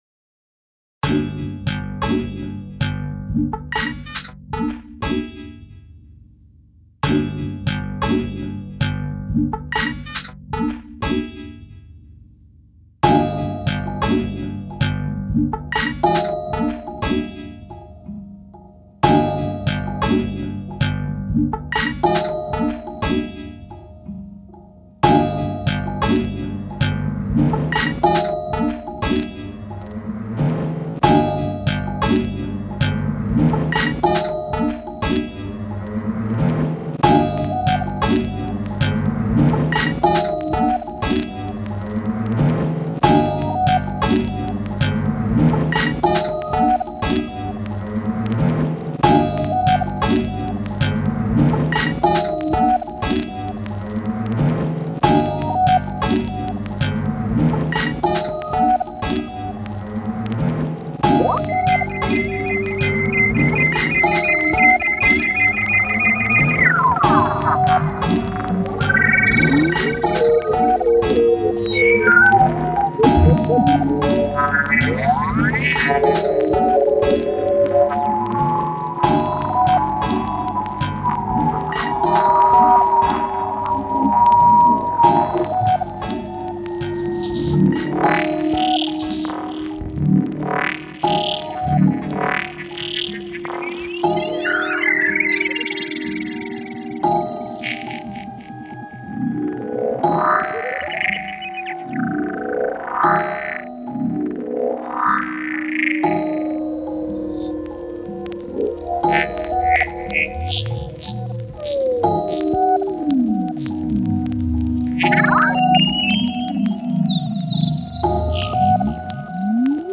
Grooved electronica.